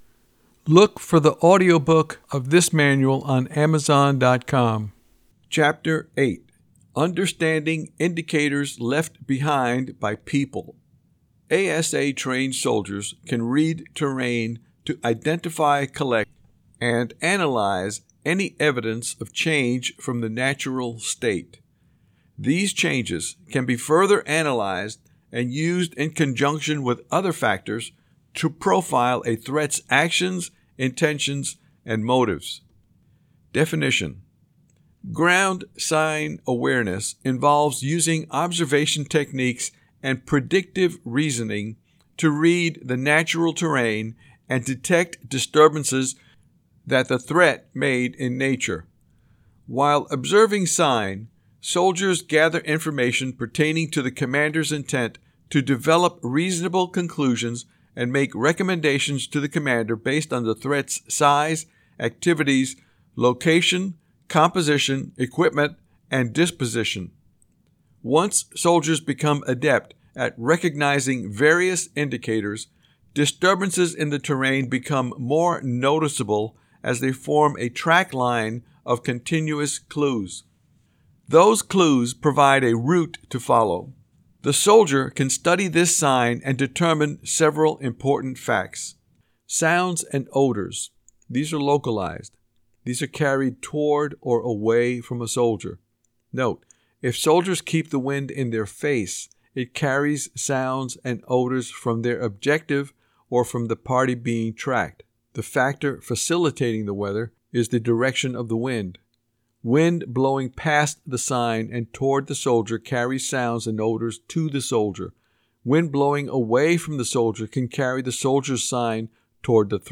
TC 3-22.69 Advanced Situational AUDIO BOOK